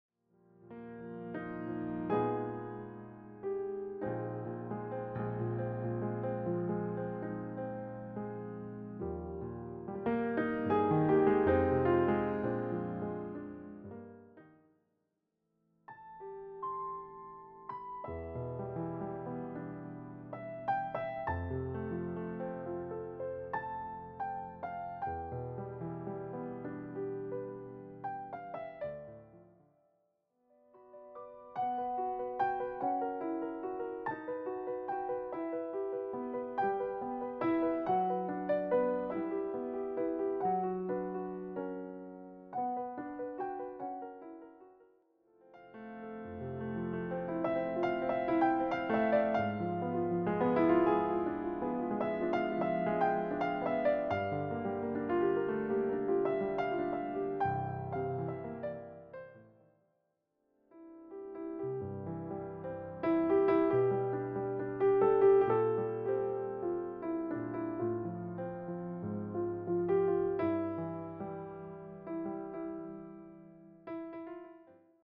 film themes, standards, and classical pieces
solo piano
a romantic thread
the gentle pace